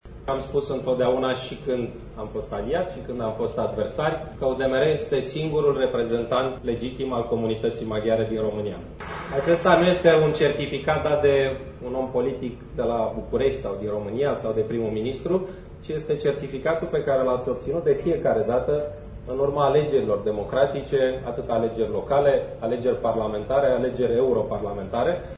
La Congresul UDMR a fost prezent şi liderul PSD, premierul Victor Ponta, care a îndemnat Uniunea să îşi păstreze principala calitate dovedită în cei 25 de ani de existenţă: promovarea dialogului politic şi interetnic: